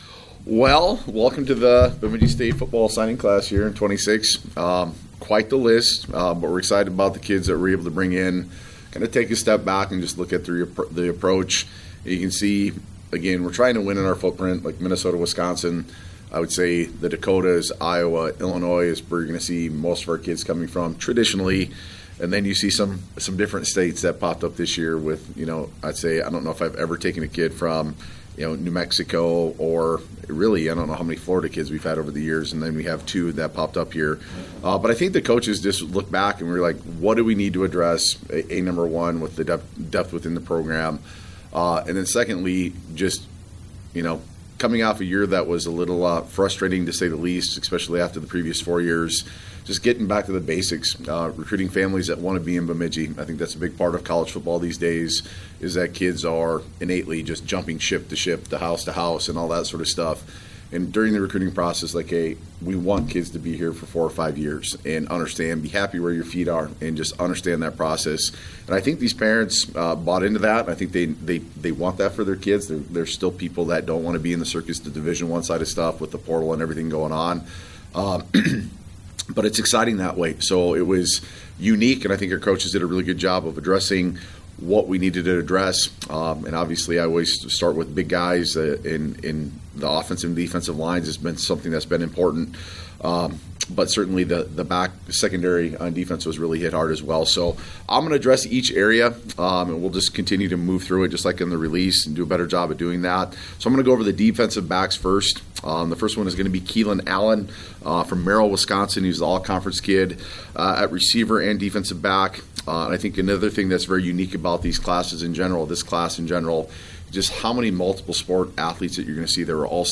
Signing Day Press Conference Signing Day Press Conference